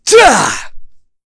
Chase-Vox_Attack2.wav